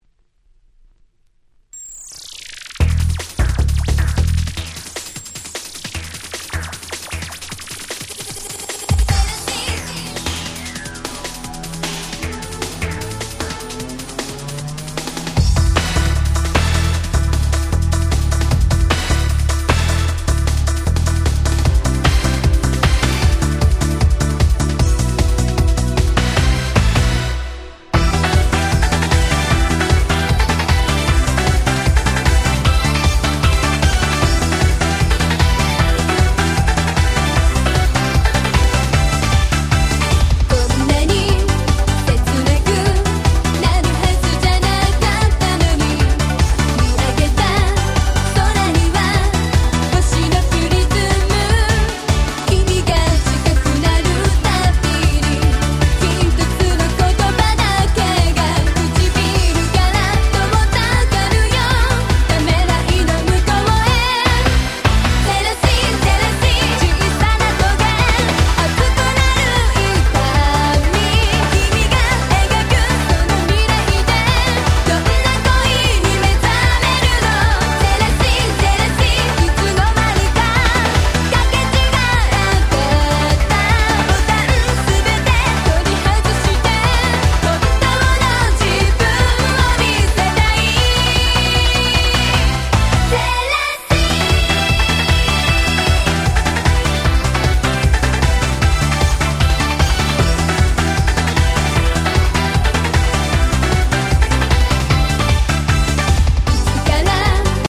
01' Very Nice J-Pop / Super Euro Beat !!